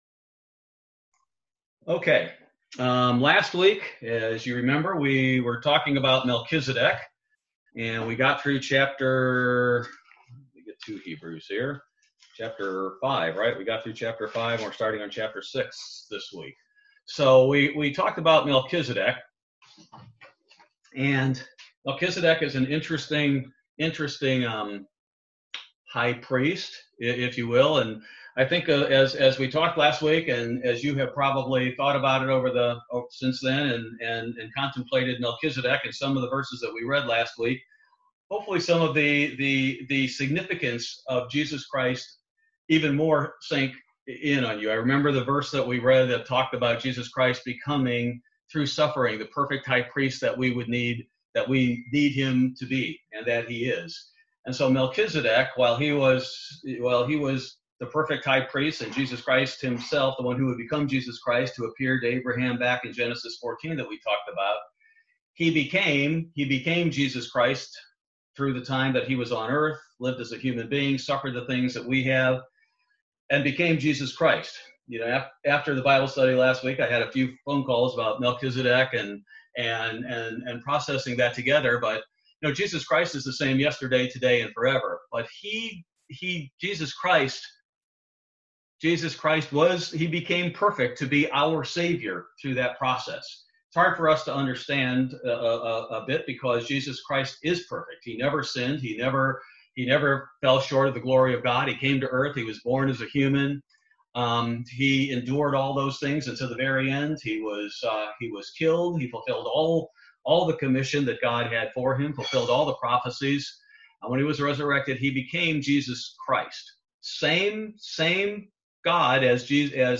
Bible Study: Hebrews 6